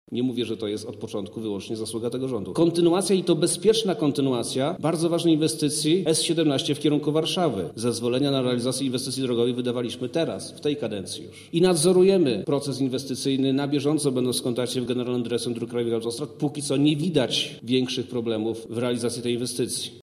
• mówi Przemysław Czarnek, Wojewoda Lubelski